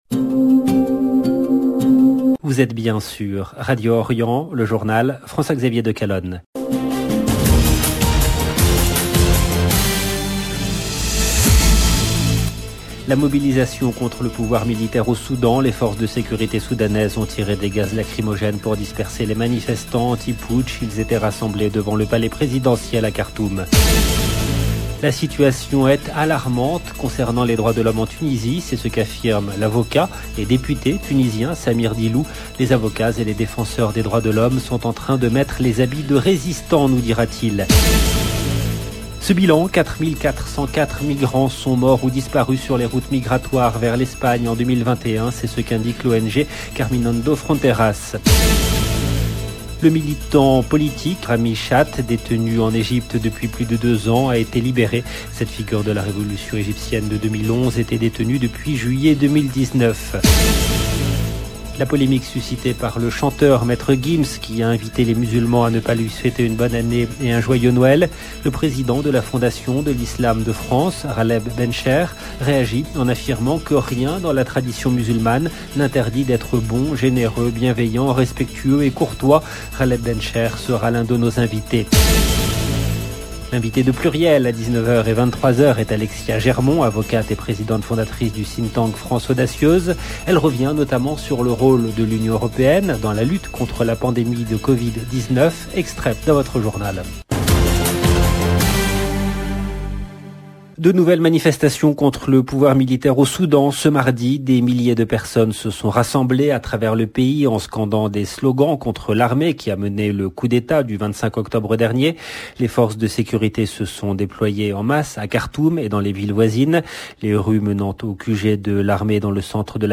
LB JOURNAL EN LANGUE FRANÇAISE